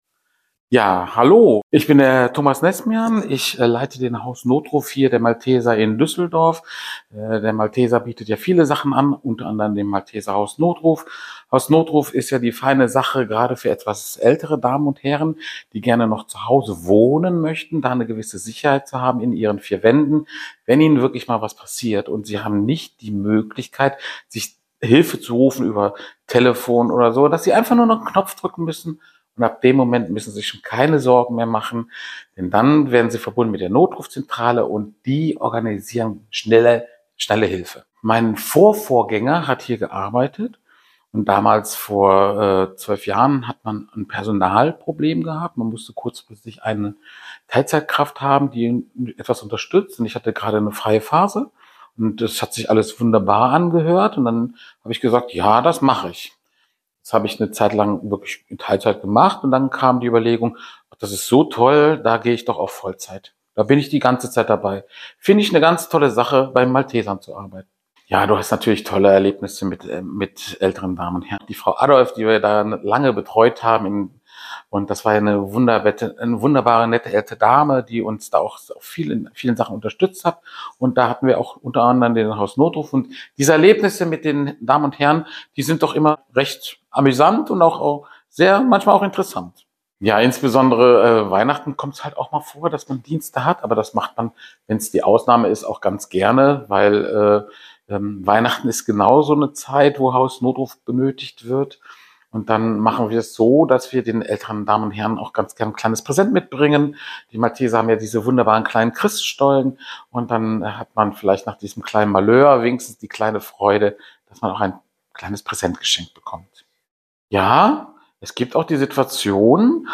In unserer Audioausstellung erzählen Mitarbeitende und Ehrenamtliche von ihrem Engagement bei den Maltesern in Düsseldorf.